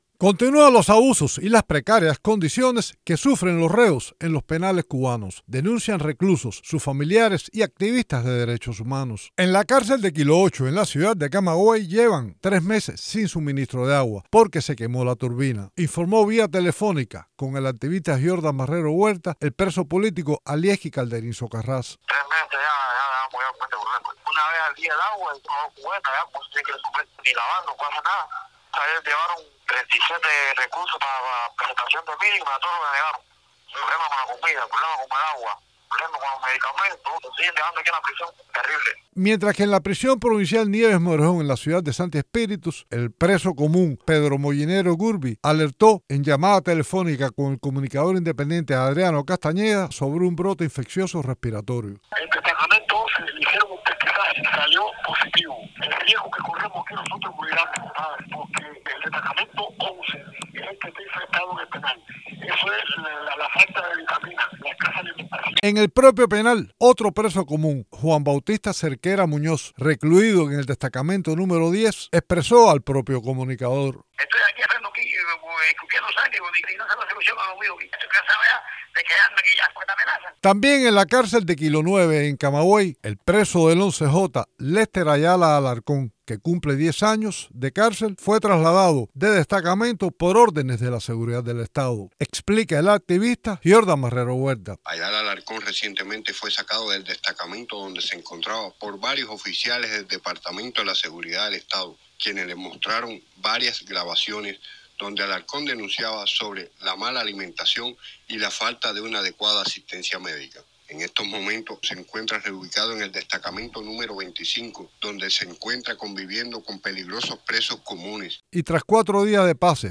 Continúan los abusos y las precarias condiciones que sufren los reos en los penales cubanos, así lo denunciaron en entrevista con Martí Noticias reclusos, sus familiares y activistas de derechos humanos.